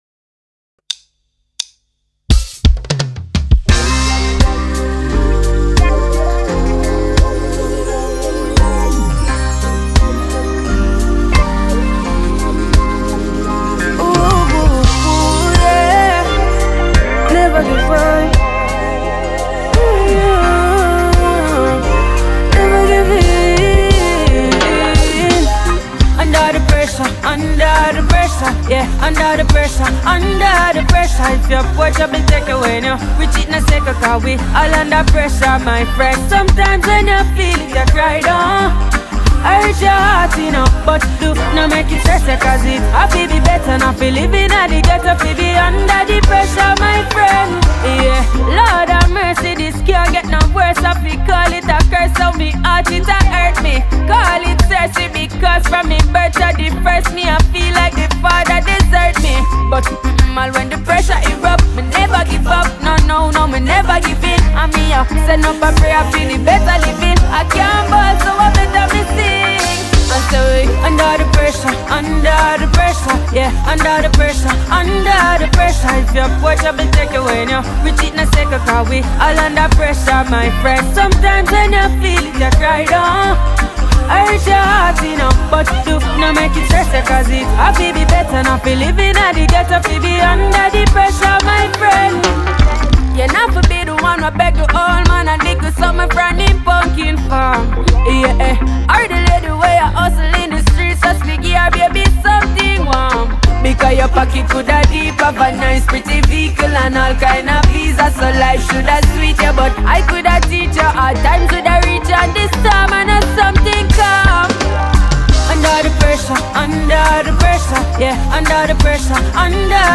amaican reggae-dancehall singer